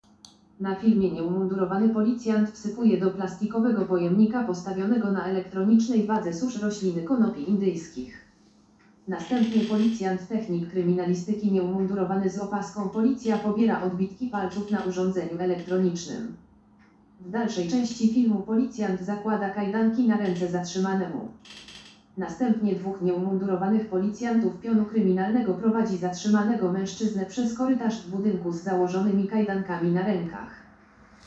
Nagranie audio audiodeskrypcja_filmu_areszt_za_narkotyki.m4a